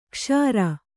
♪ kṣāra